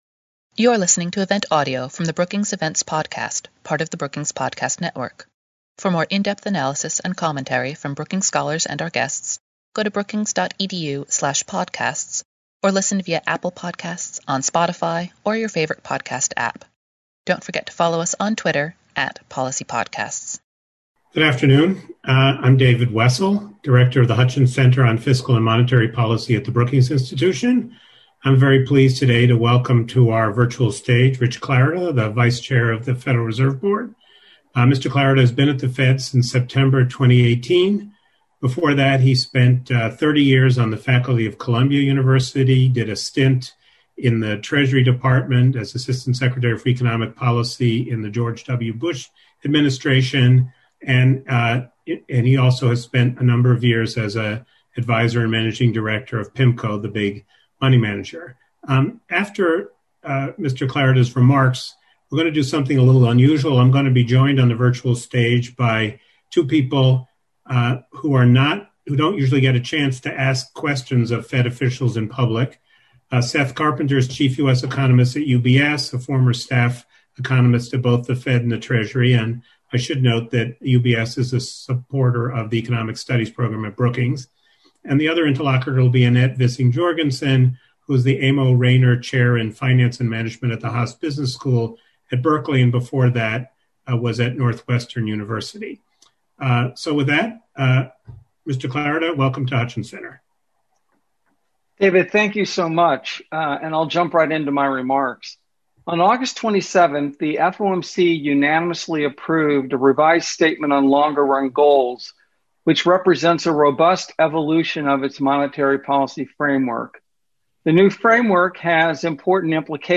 The economy and monetary policy: A conversation with Fed Vice Chair Richard Clarida | Brookings